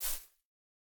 Minecraft Version Minecraft Version latest Latest Release | Latest Snapshot latest / assets / minecraft / sounds / block / azalea / step2.ogg Compare With Compare With Latest Release | Latest Snapshot
step2.ogg